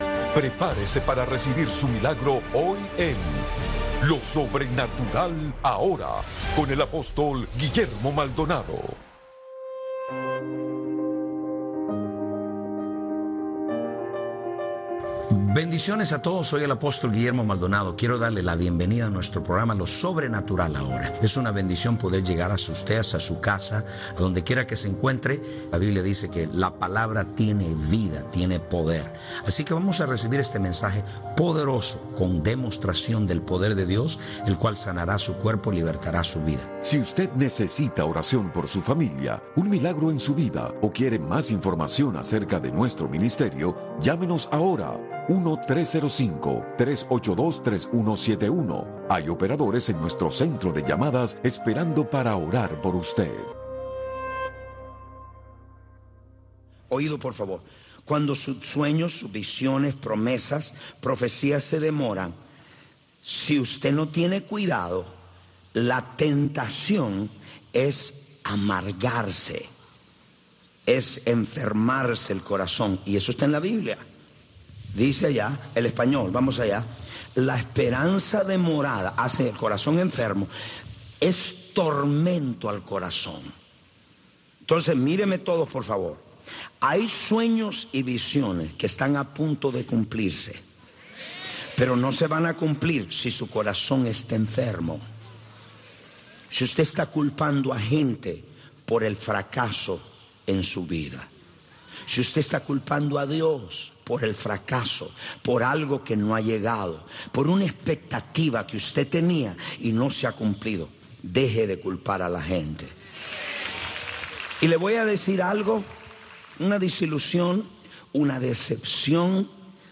Radio Martí te presenta todos los sábados y domingos entre 6 y 8 de la mañana el bloque religioso “La Religión en Martí” en donde te presentaremos diferentes voces de académicos, pastores y hombres de fe que te traerán la historia y la palabra esperanzadora del señor.